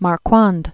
(mär-kwŏnd), John Phillips 1893-1960.